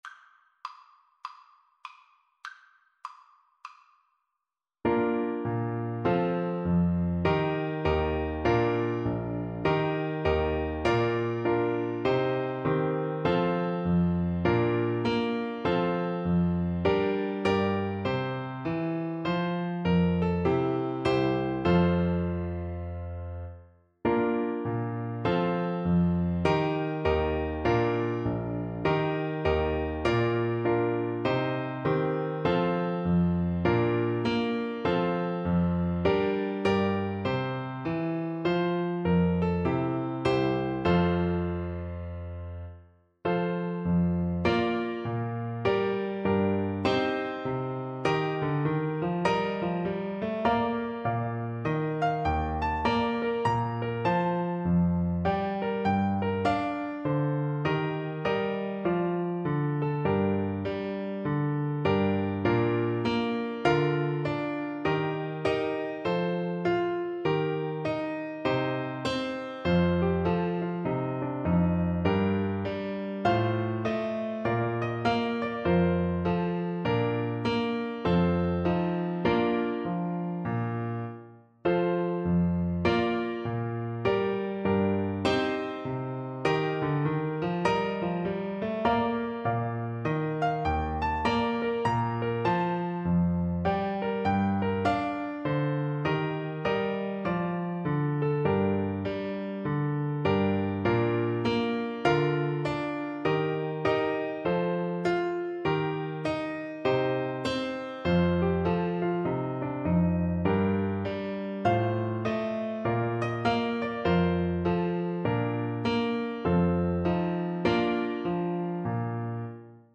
Traditional Turlough O Carolan Carolan's Draught Alto Saxophone version
Alto Saxophone
Bb major (Sounding Pitch) G major (Alto Saxophone in Eb) (View more Bb major Music for Saxophone )
2/2 (View more 2/2 Music)
Traditional (View more Traditional Saxophone Music)